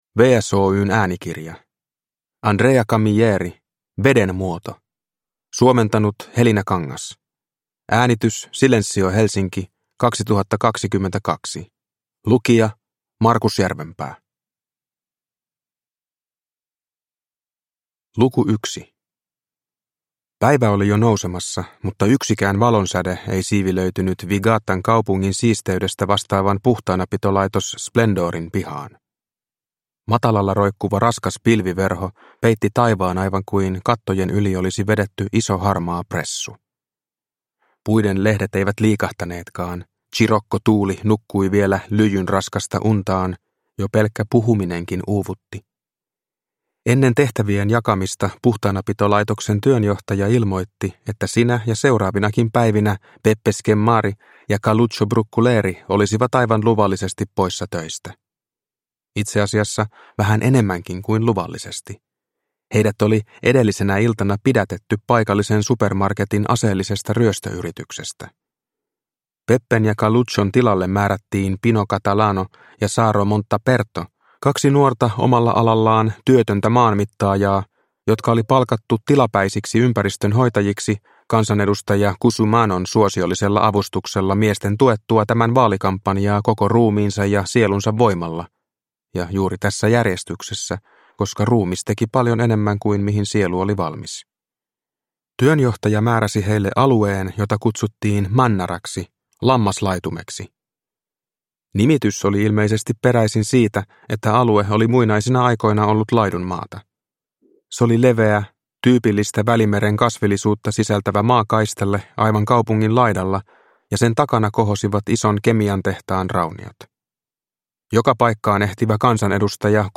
Veden muoto – Ljudbok – Laddas ner